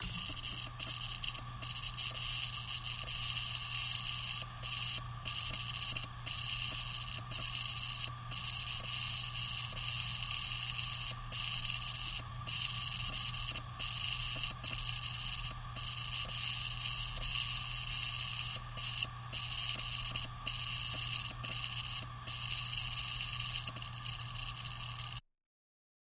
Звуки жесткого диска
поцарапанный жесткий диск